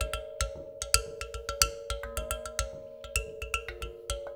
APH M BIRA-L.wav